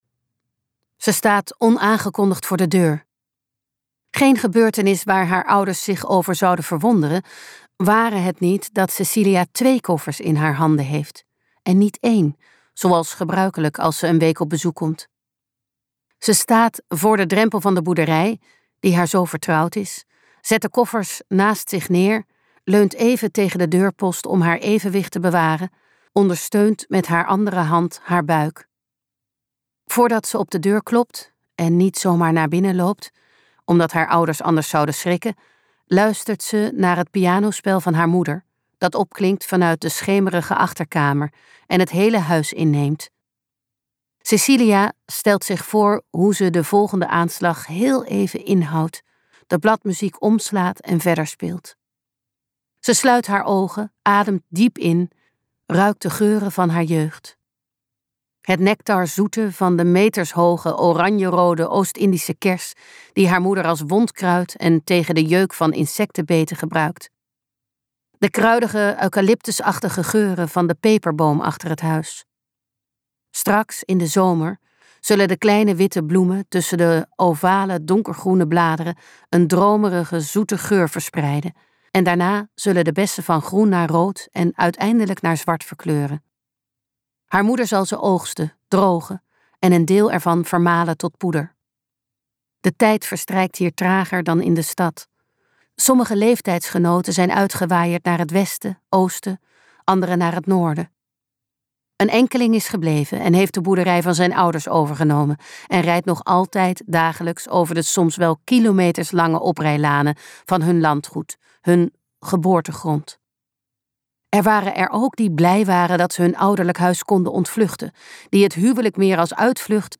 Ambo|Anthos uitgevers - Een verdwaalde zomerdag luisterboek